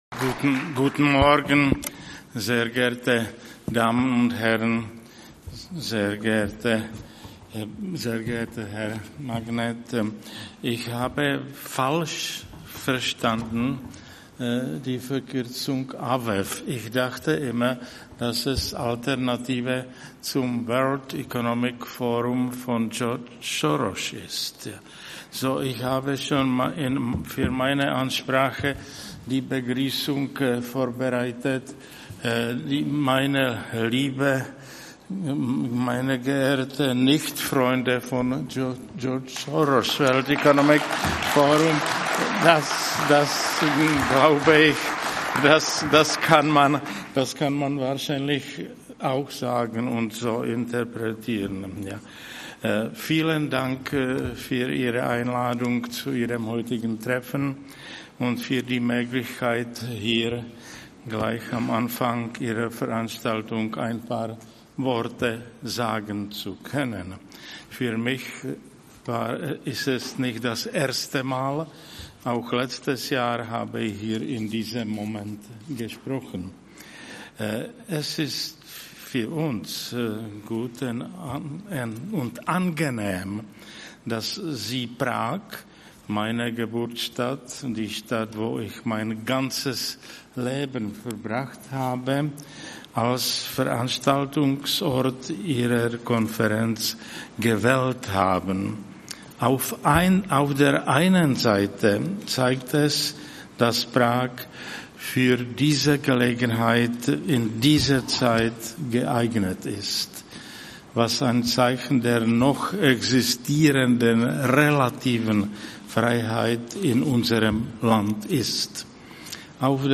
Eröffnungsrede Prof. Dr. Václav Klaus ~ AUF1 Podcast